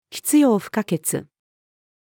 必要不可欠-female.mp3